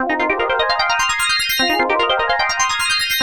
FILTERORGANX 2.wav